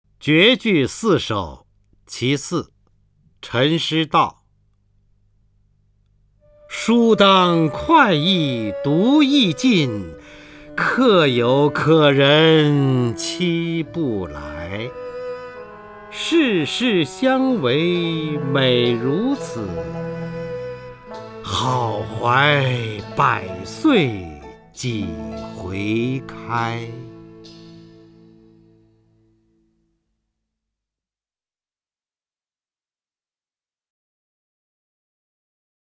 首页 视听 名家朗诵欣赏 方明
方明朗诵：《绝句·书当快意读易尽》(（北宋）陈师道)